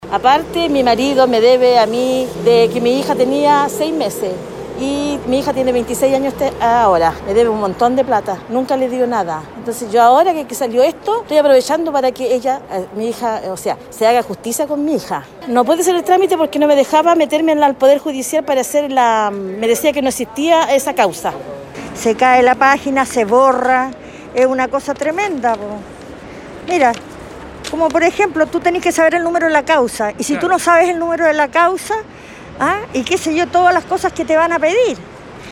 Igualmente, Radio Bío Bío conversó con con personas que estaban afuera de los Tribunales de Familia, principalmente mujeres, quienes confirmaron que no les dan respuesta a sus inquietudes.